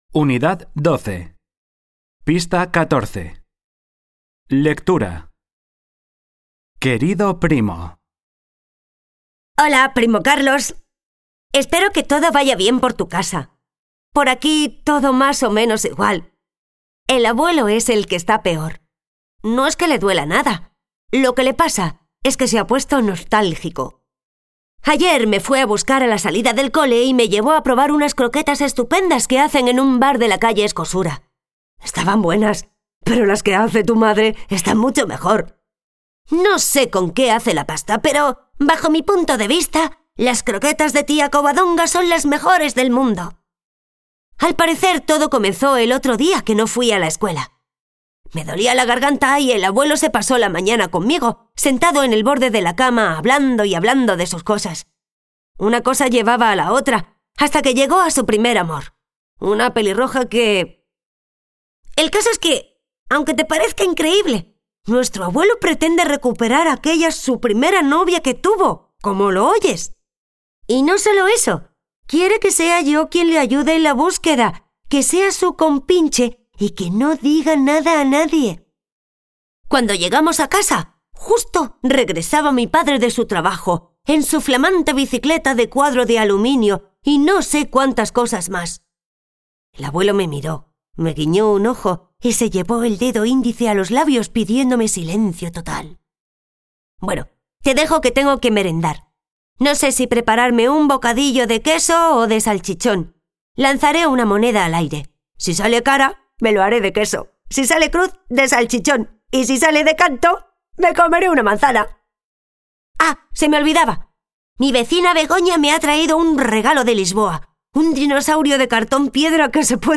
4º_Lengua_AUDIO LECTURA U12 QUERIDO PRIMO